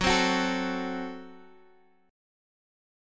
F#dim chord